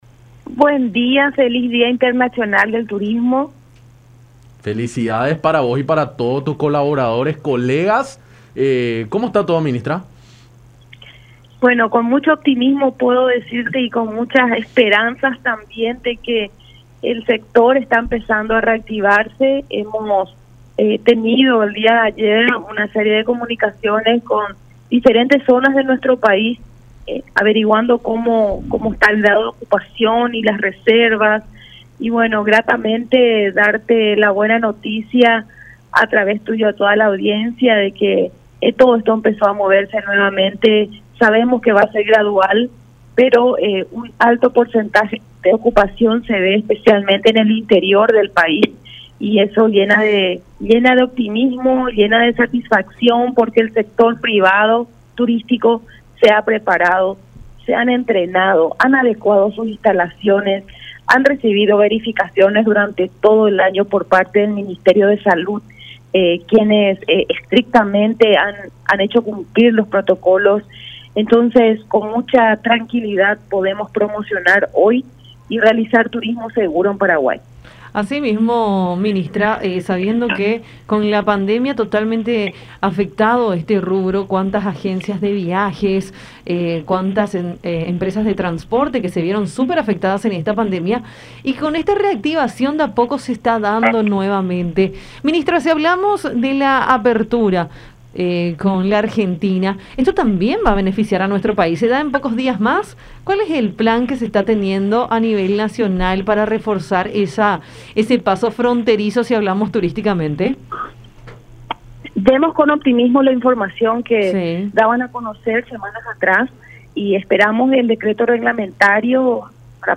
“El sector del turismo comenzó a moverse, si bien será de manera gradual, ya se está comenzando a ver eso y llena de satisfacción”, aseveró Montiel en diálogo con Enfoque 800 por La Unión, destacando que en gran parte de la Región Oriental el movimiento de turistas ha sido importante durante el fin de semana largo.